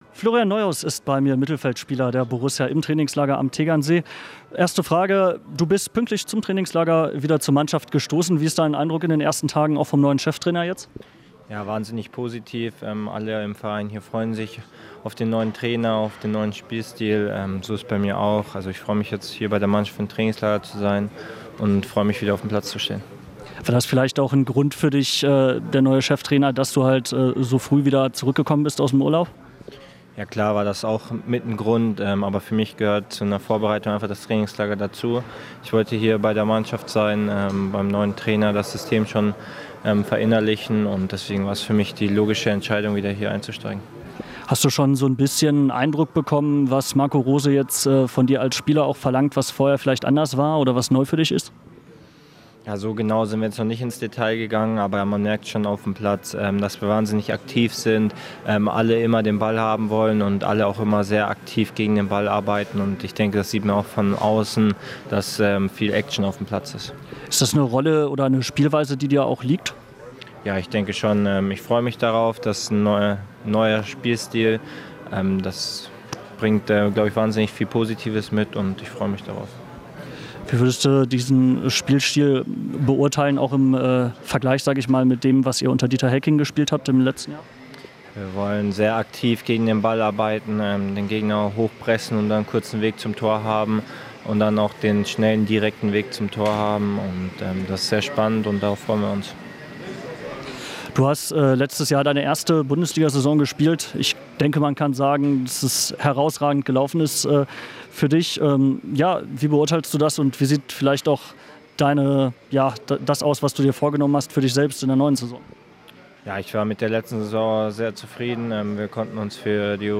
Borusse Florian Neuhaus im Radio 90,1-Exklusivinterview
Wir haben im Trainingslager am Tegernsee exklusiv mit Florian Neuhaus von Borussia Mönchengladbach gesprochen.
interview-florian-neuhaus-komplett-v1.mp3